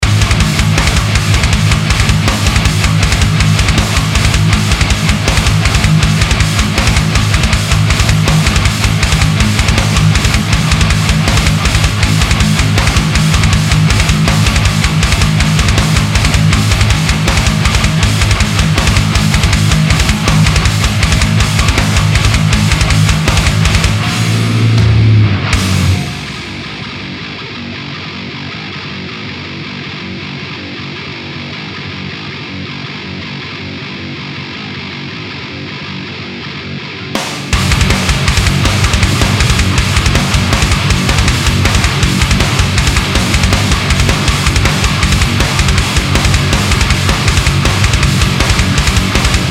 ��� ��������?������ - POD X3 - MesaRectifierV30s-SM57-CapOffAxis-0in.wav - EQ (High Pass �� 125��) (100, 80, 80, 100)��� - POD ...